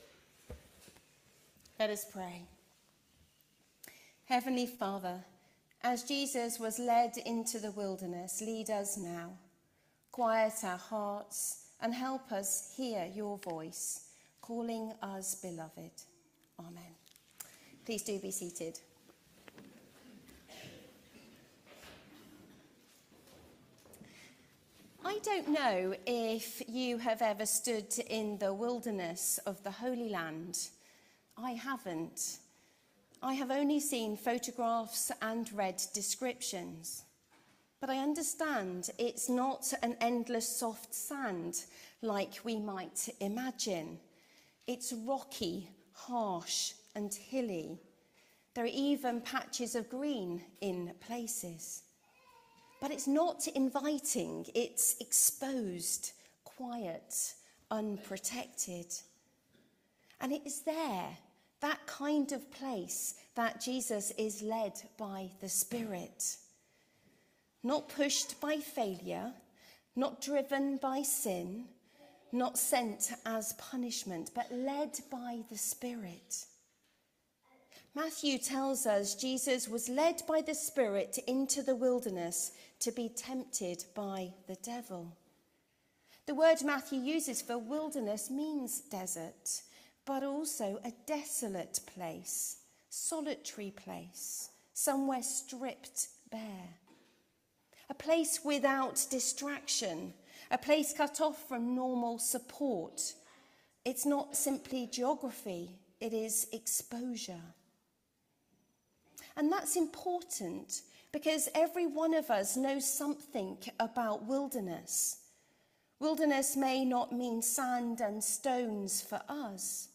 This is the Gospel of the Lord All Praise to you, O Christ Series: Lent , Sunday Morning